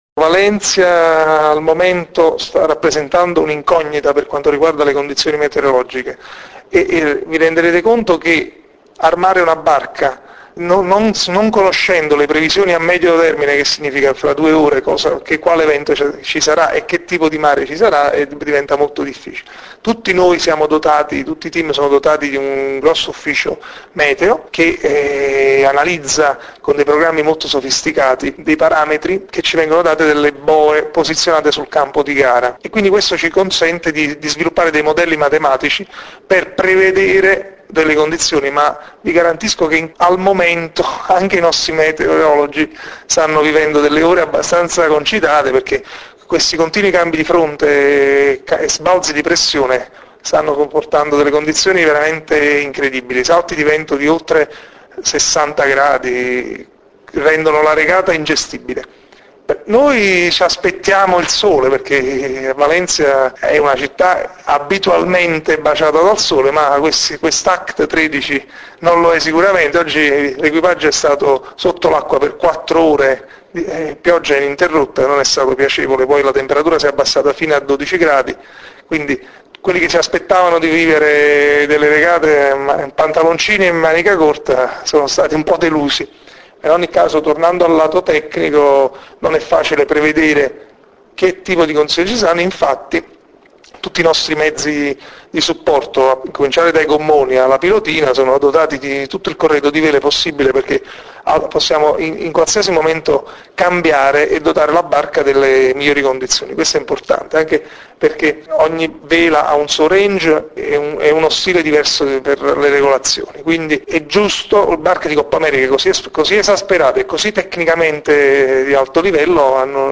Commento su condizioni meteo di Davide Tizzano, grinder a bordo di Mascalzone Capitalia Team